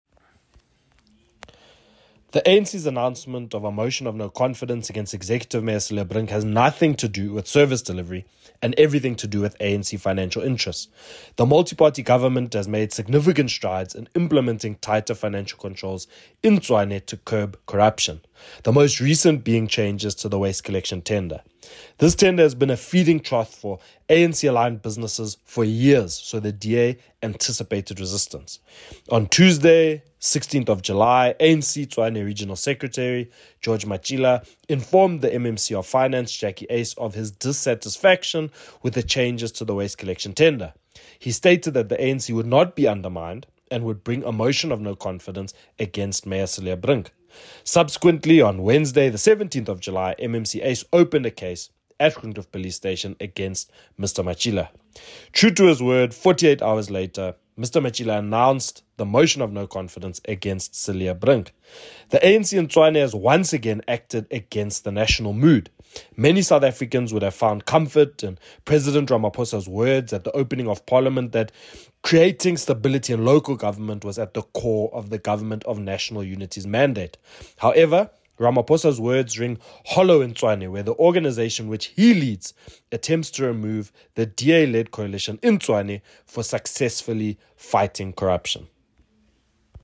Issued by Cllr Kwena Moloto – DA Tshwane Spokesperson
Note to Editors: Please find an English soundbite by Cllr Kwena Moloto